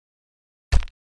物品栏放物品zth070509.wav
通用动作/01人物/06工作生产/物品栏放物品zth070509.wav
• 声道 單聲道 (1ch)